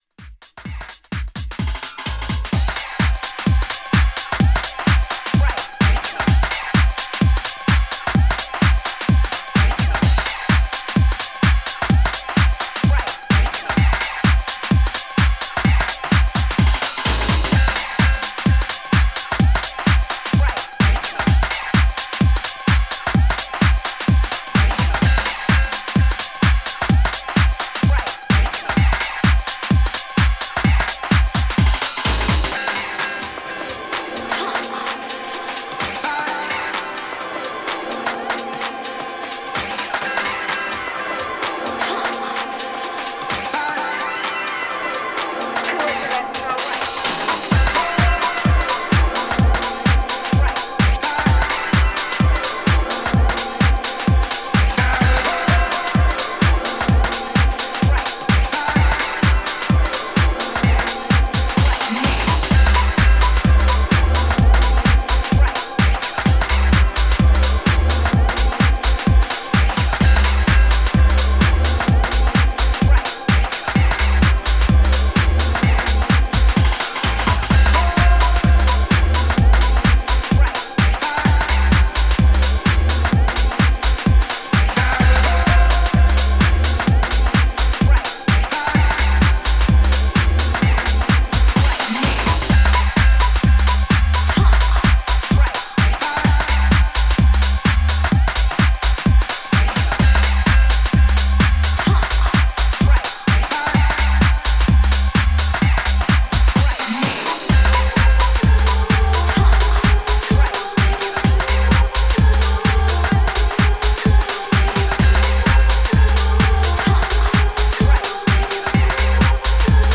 ガラージ好きにもおすすめしたいオールドスクールな雰囲気を持ったファンクショナルなモダン・テックハウス集です。